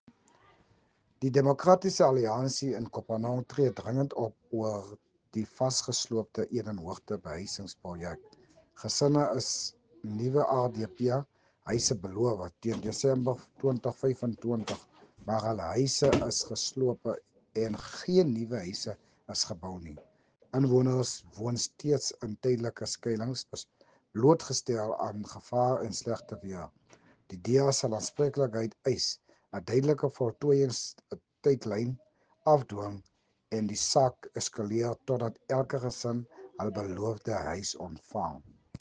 Afrikaans soundbites by Cllr Richard van Wyk and